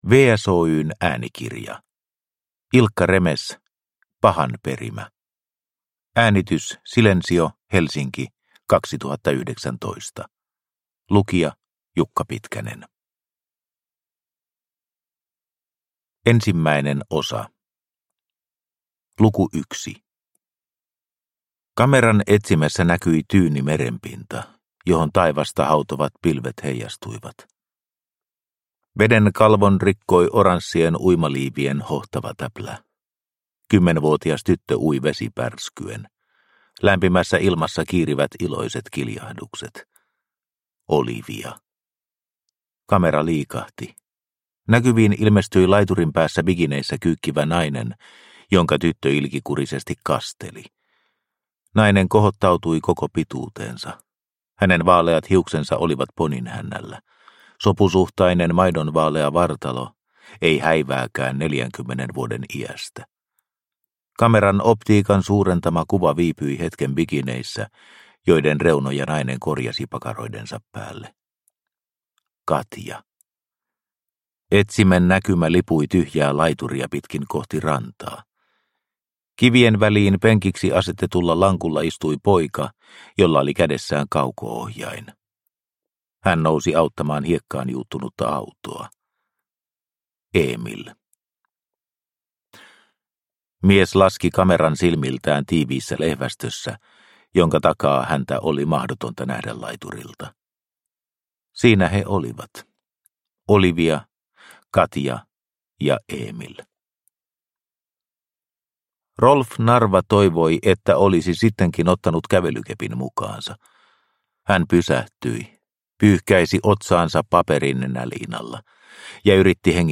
Pahan perimä – Ljudbok – Laddas ner